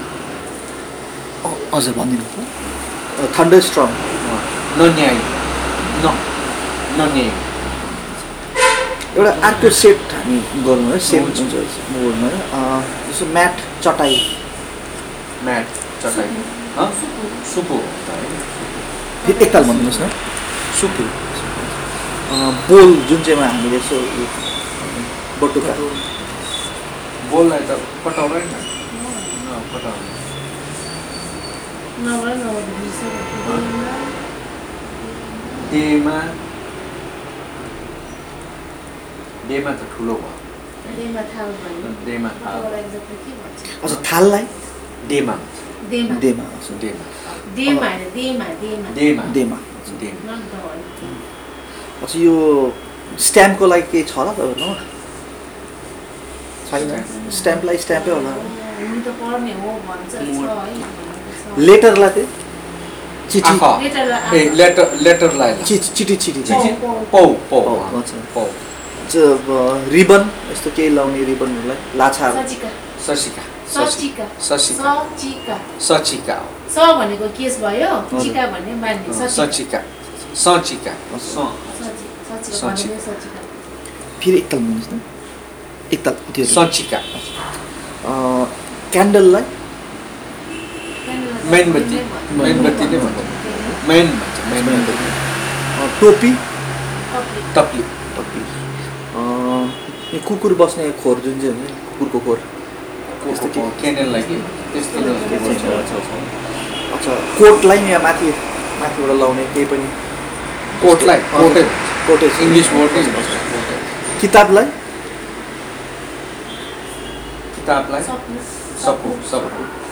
Elicitation of words about adornments and costumes, and artifacts and household items